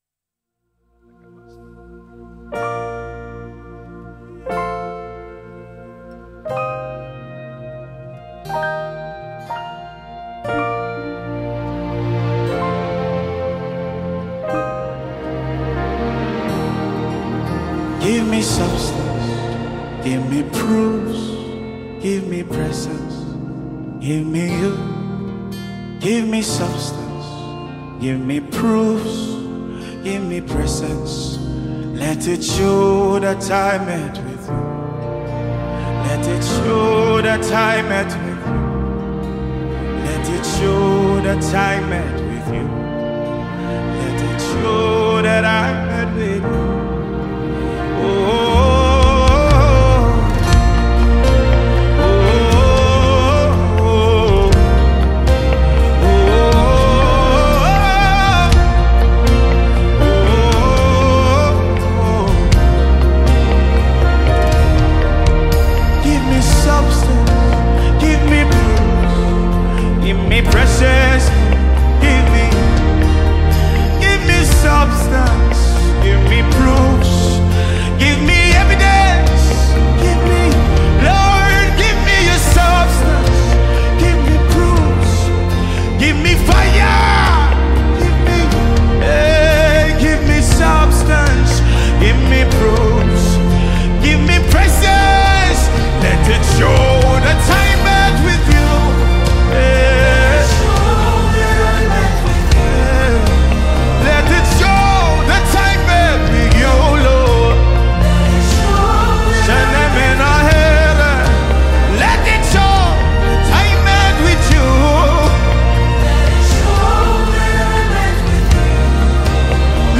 Nigeria talented gospel music singer and songwriter